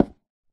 Sound / Minecraft / dig / wood1